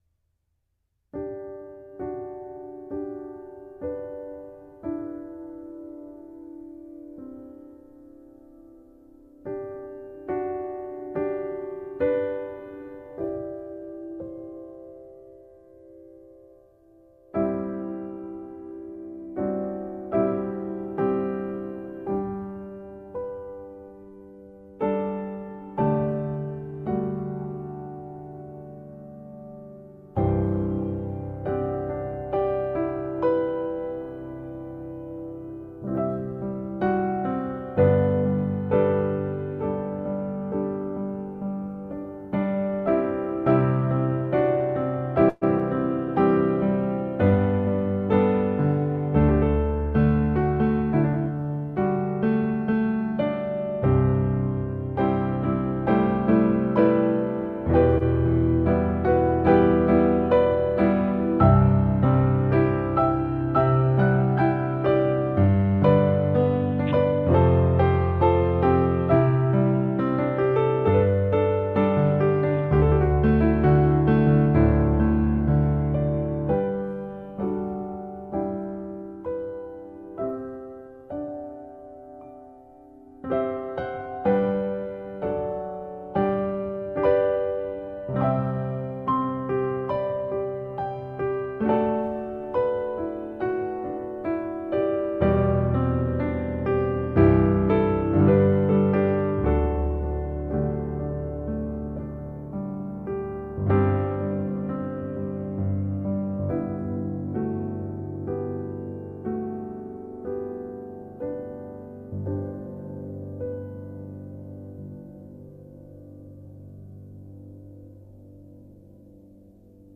Non è una fanfara motivazionale epica.
Si sente quando qualcosa, dentro un paesaggio più scuro, comincia a muoversi.